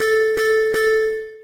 Techmino/media/effect/chiptune/warn_2.ogg at beff0c9d991e89c7ce3d02b5f99a879a052d4d3e
warn_2.ogg